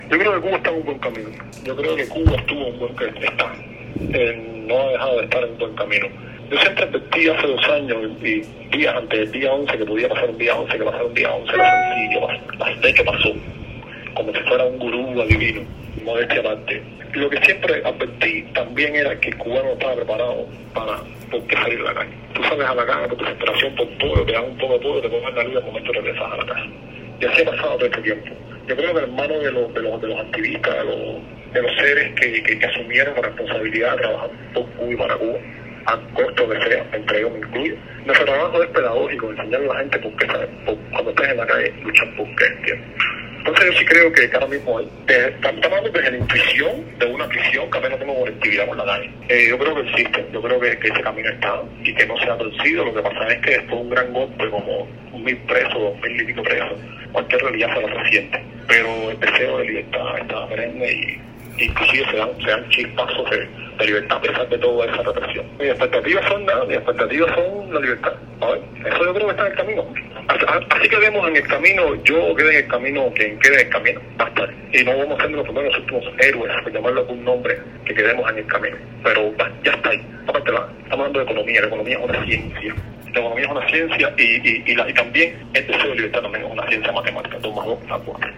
Otero Alcántara habla desde prisión para Martí Noticias